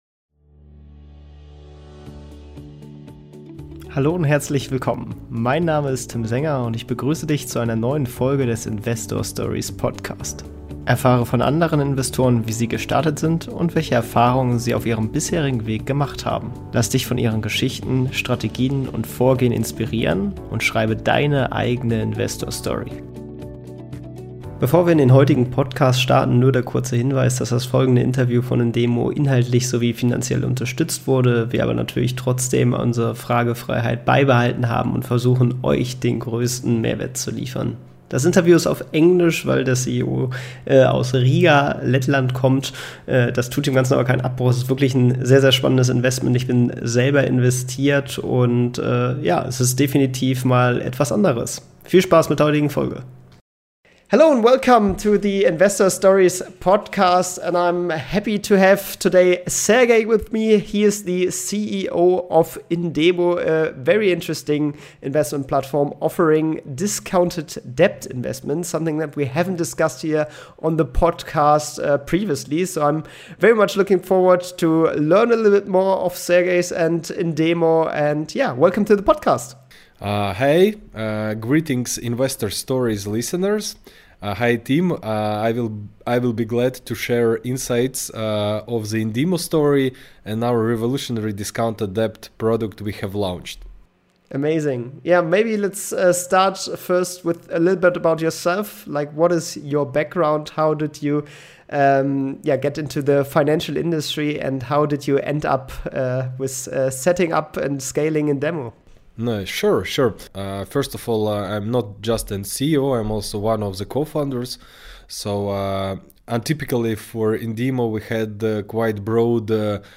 Beschreibung vor 2 Wochen Zweistellige Renditen mit Investitionen in ausgefallene oder notleidende Hypothekendarlehen, klingt erstmal abwegig, aber wenn man diese Forderungen zu erheblichen Abschlägen kauft, so kann man seinem Portfolio eine attraktive diversifizierende Anlageklasse hinzufügen. Im Interview